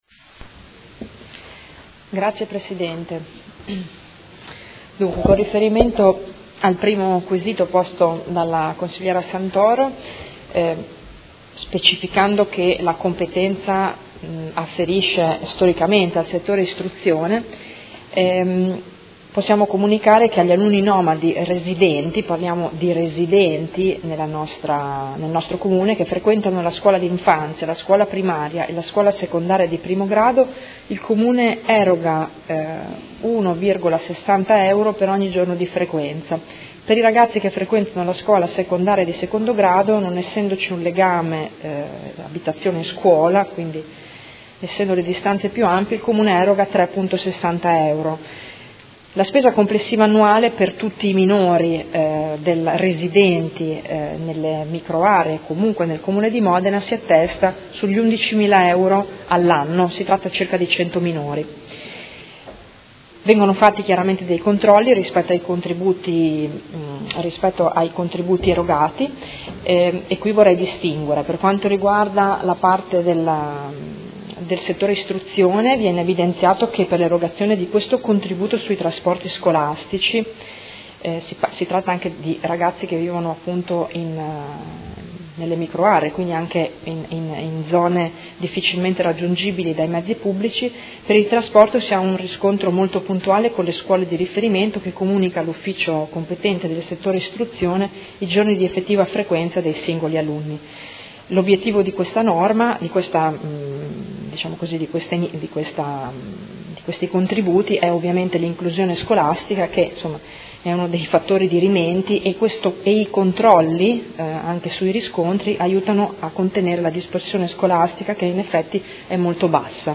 Seduta del 22/11/2018. Risponde a interrogazione della Consigliera Santoro (Lega Nord) avente per oggetto: Microaree nomadi